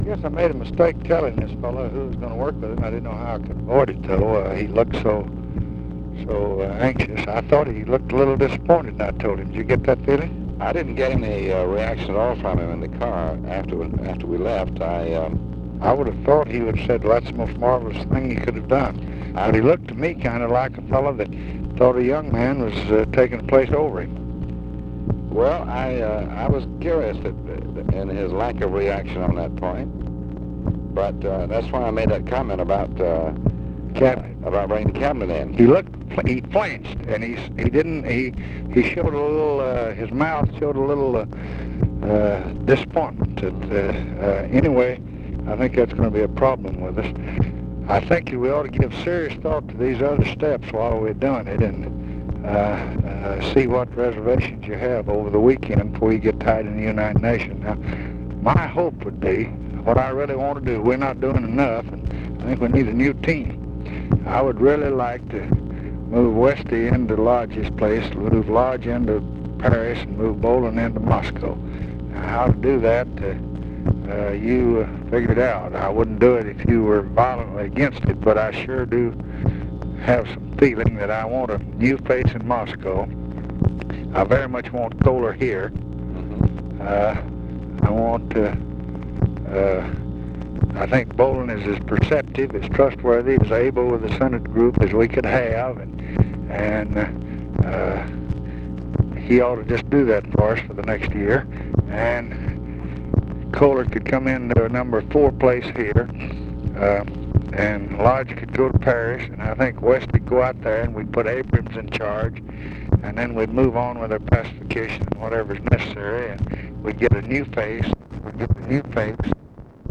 Conversation with DEAN RUSK, September 17, 1966
Secret White House Tapes